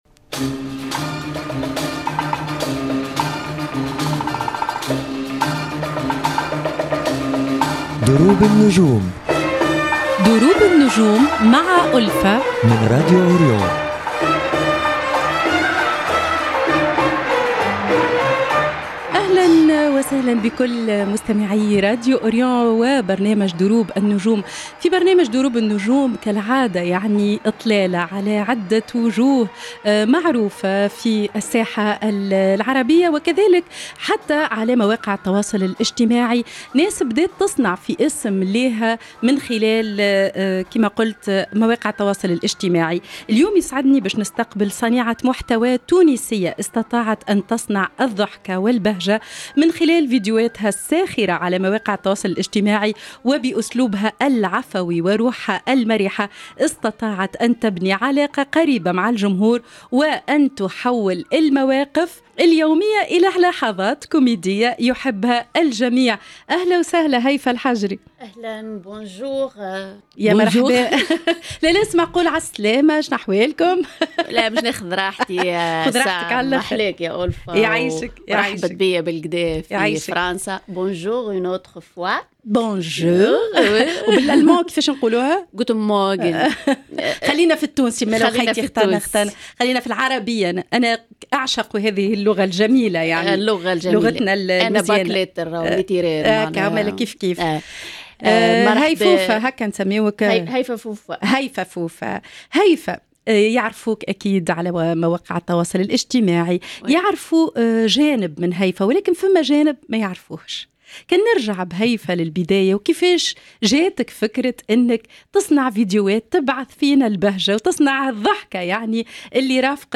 في لقاء مليء بالضحك والصراحة والقصص التي تروى لأول مرة في دروب النجوم